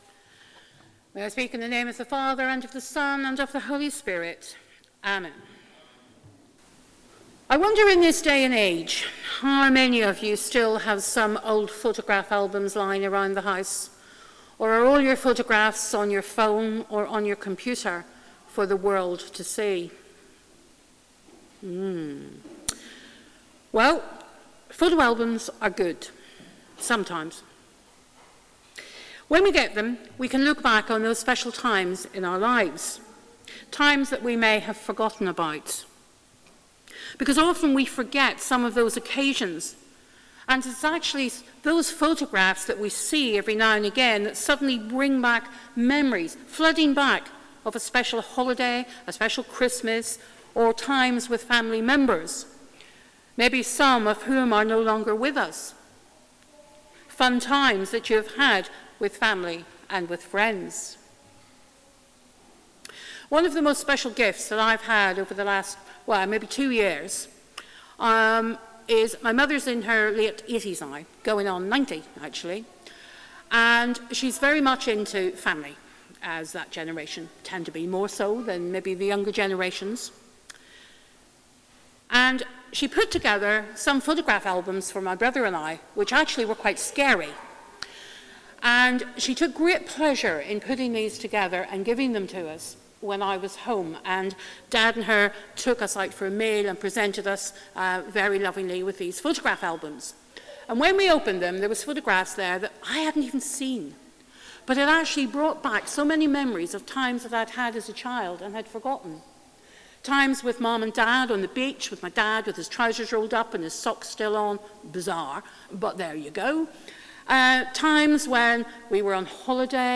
Sermon: Faith | St Paul + St Stephen Gloucester